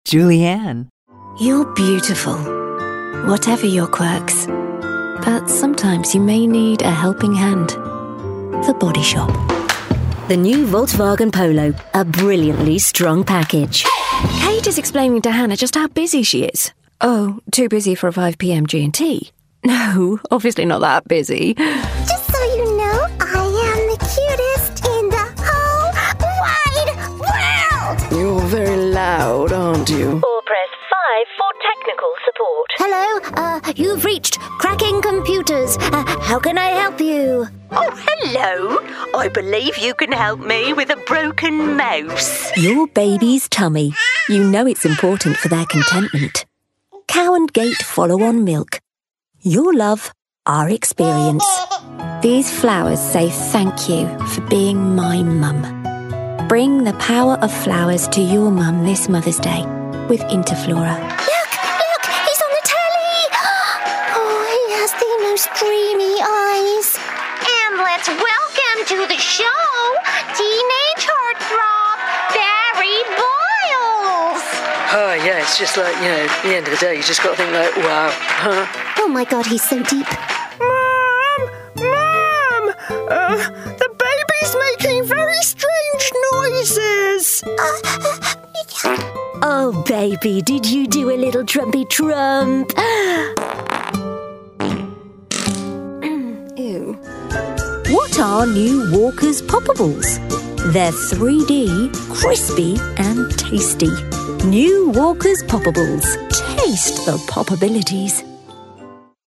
Showcase Demo
accented English, announcer, anti-announcer, authoritative, british, caring, classy, compelling, confident, conversational, corporate, english-showcase, friendly, genuine, Gravitas, informative, middle-age, neutral, professional, promo, real, serious, sincere, smooth, sophisticated, thoughtful, upbeat, warm, worldly
British